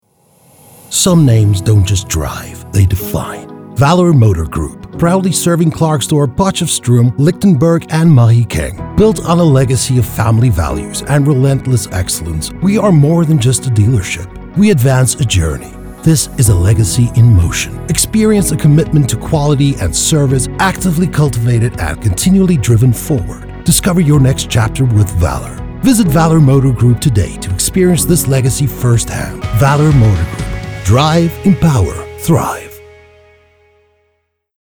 authoritative, Deep, raspy
Medium Sell | American accent